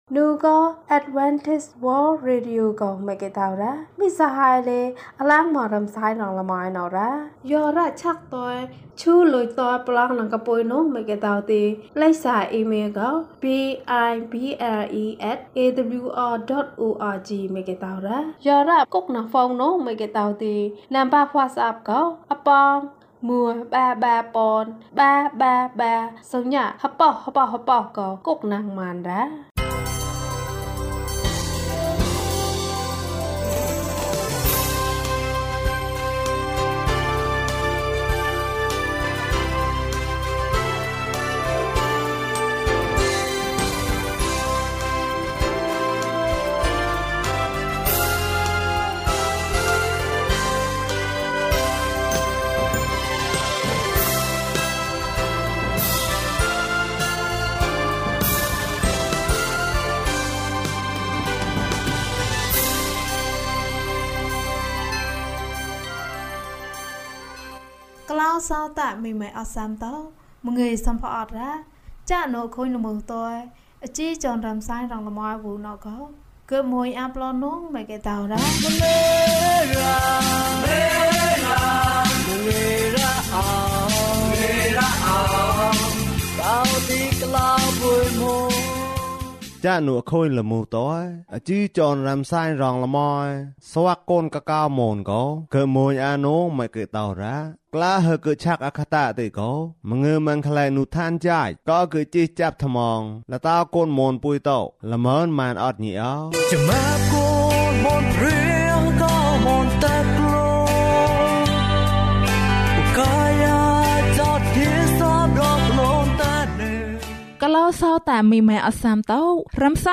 ယေရှုက သင့်ကို ချစ်တယ်။ ကျန်းမာခြင်းအကြောင်းအရာ။ ဓမ္မသီချင်း။ တရားဒေသနာ။